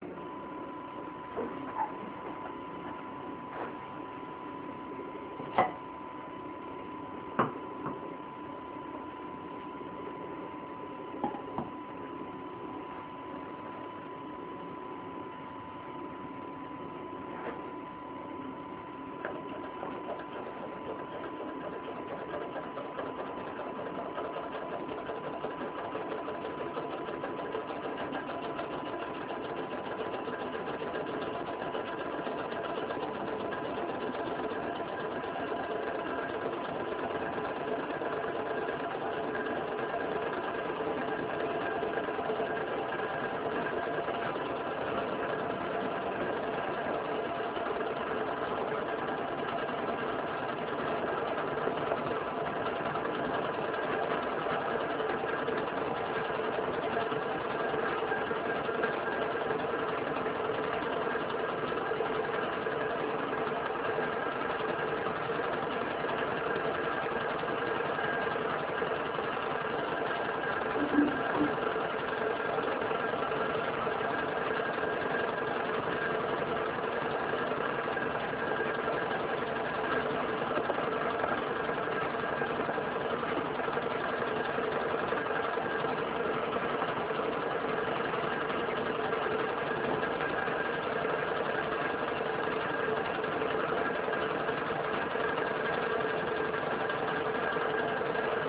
This model works very well and turn at a high speed on a simple coffee cup filled with water and heated in a simple microwaves oven...